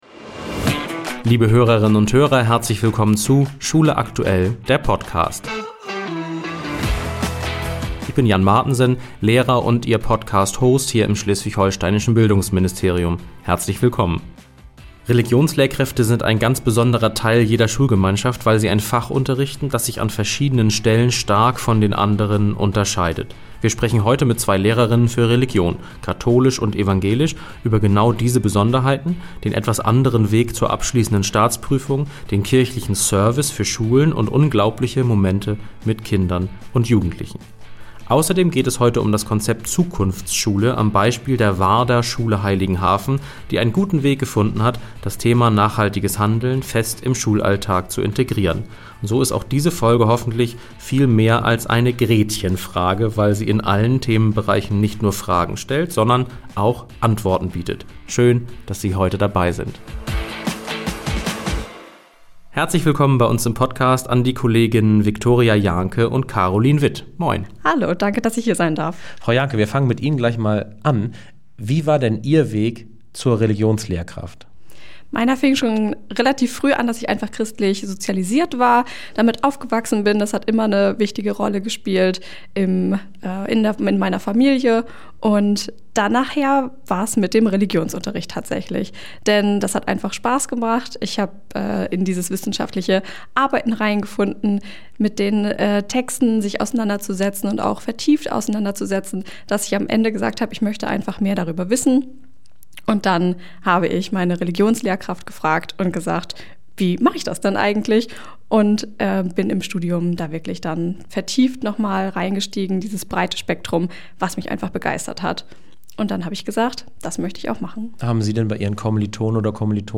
Religion ist ein in vielfacher Hinsicht besonderes Fach. Wir sprechen heute mit zwei Lehrerinnen – katholisch und evangelisch – über diese Besonderheiten, den etwas anderen Weg zur abschließenden Staatsprüfung, den kirchlichen Service für Schulen...